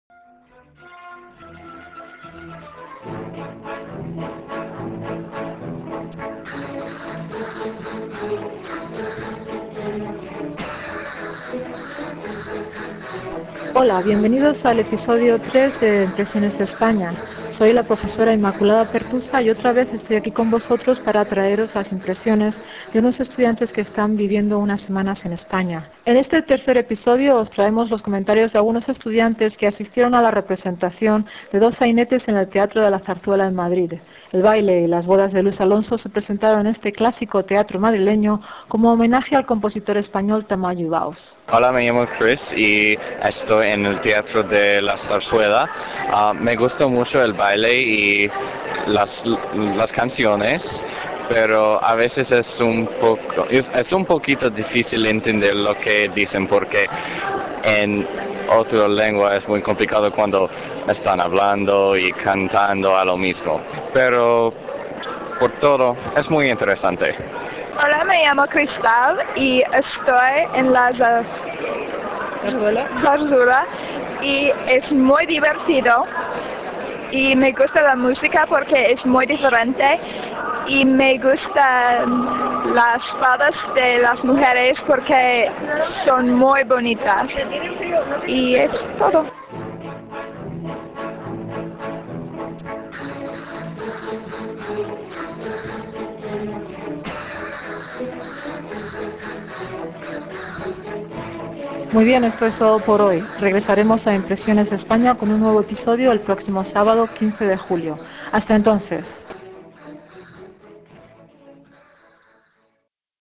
Dos estudiantes del grupo de KIIS nos hablan sobre los dos sainetes que vieron en el Teatro de la Zarzuela de Madrid.